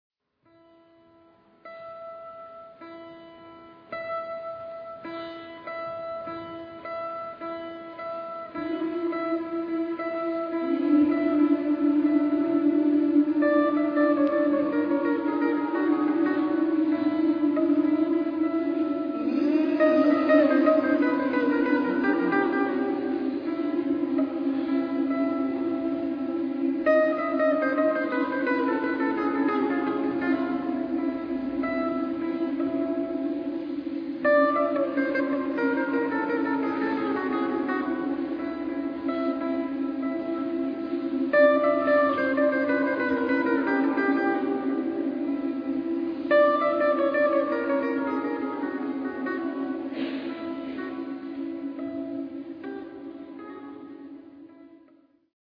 SA (2 Kinderchor Stimmen) ; Partitur.
Partitur Beschreibung Sprache: gesummt Zeitepoche
Charakter des Stückes: Taktwechsel
Instrumentation: E-Bass
Instrumente: E-Gitarre